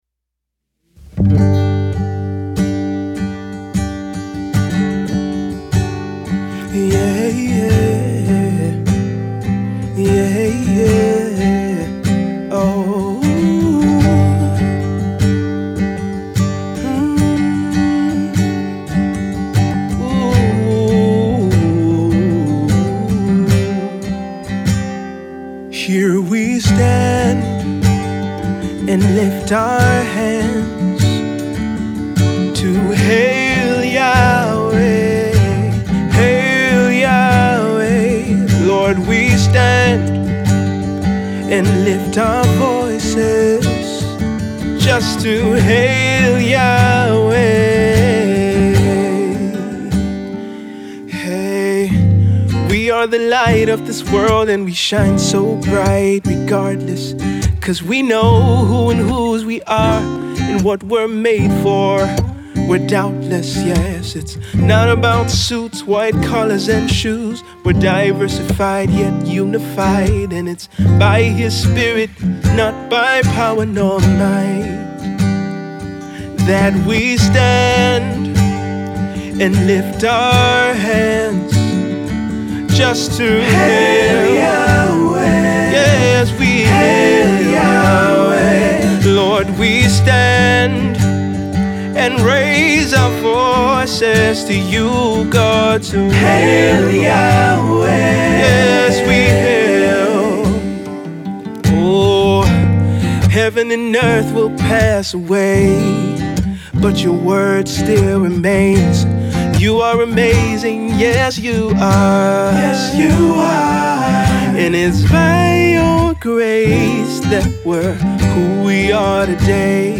a song of allegiance and worship